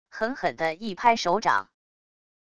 狠狠的一拍手掌wav音频